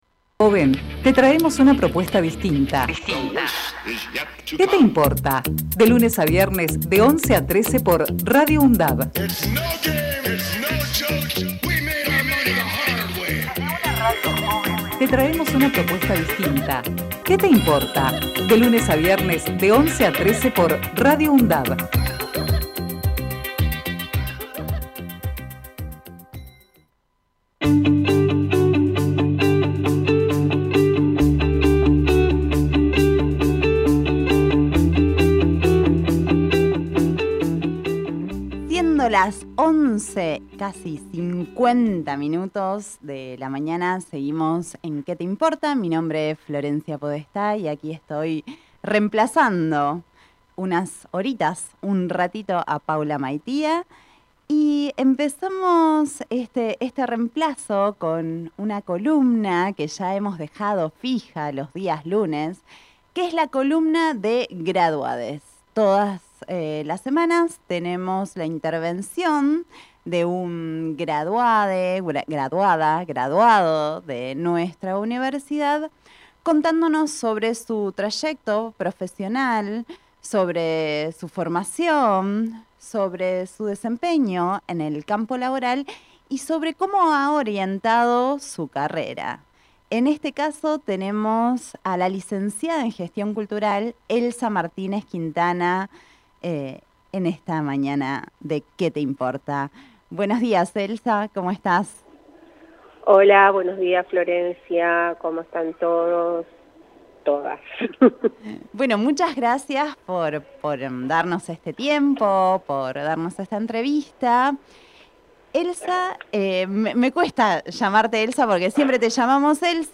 ENTREVISTA EN "QUE TE IMPORTA" A LA LIC.